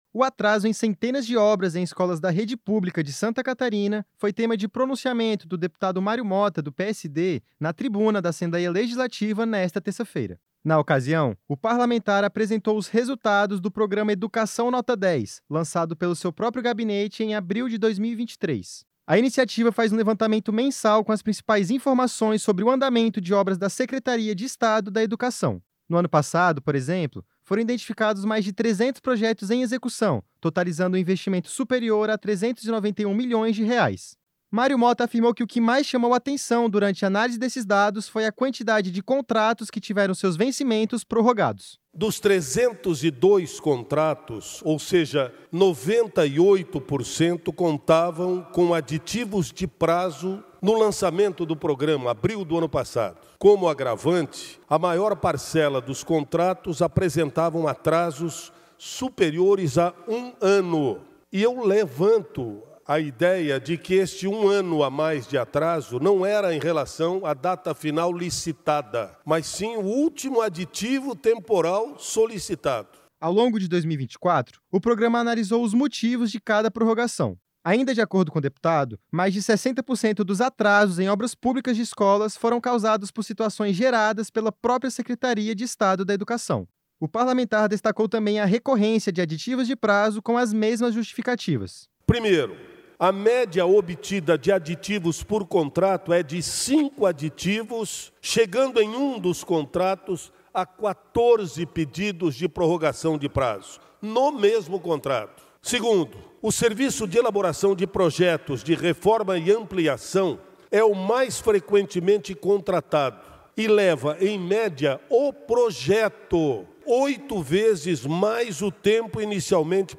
Entrevista com:
- deputado Mário Motta (PSD).